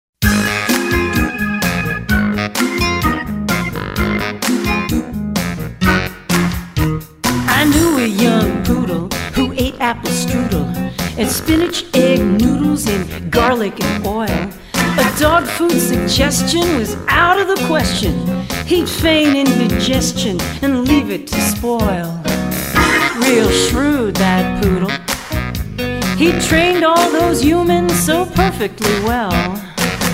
Vocal and